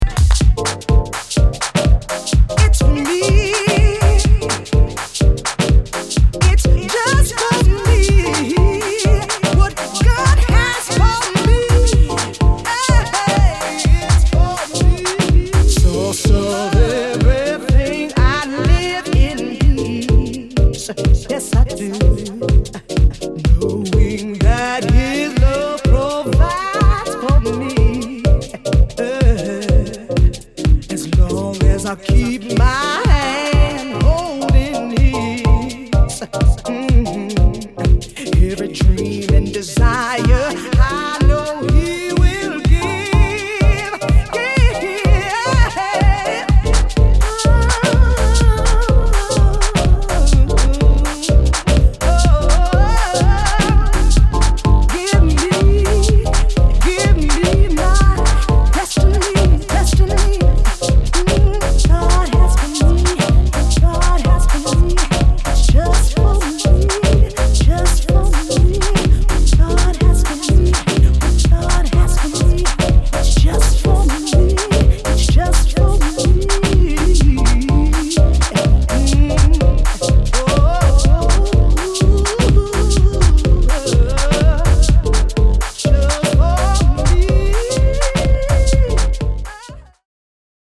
ジャンル(スタイル) JAZZY HOUSE / SOULFUL HOUSE / DETROIT HOUSE